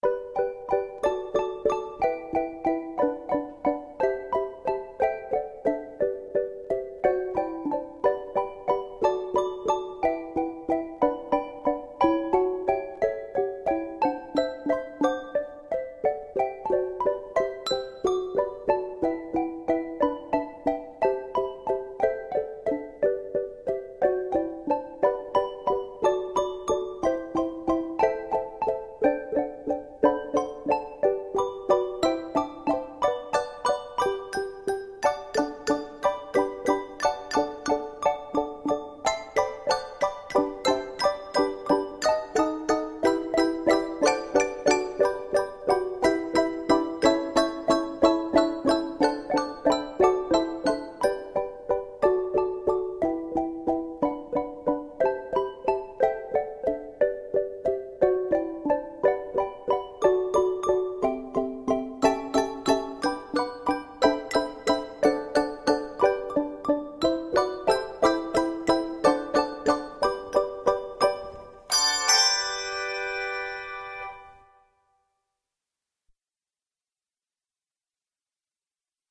6/8 gospel song